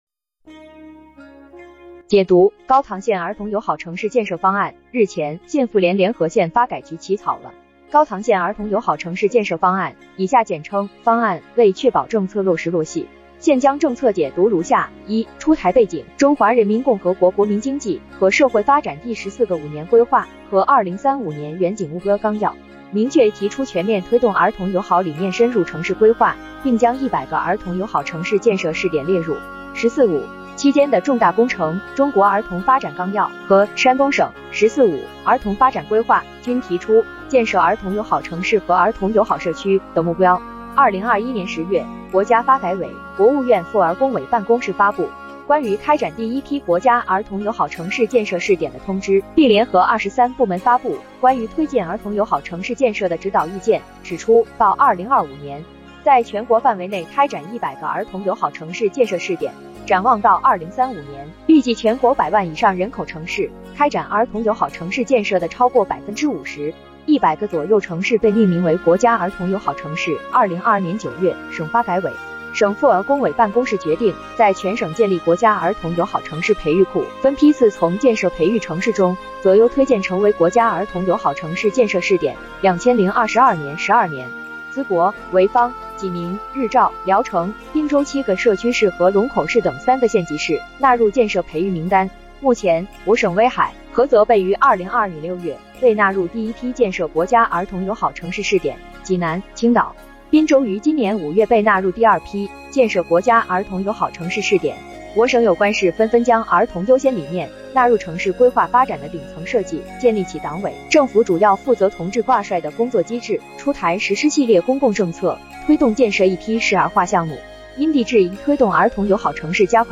音频解读】高唐县儿童友好城市建设方案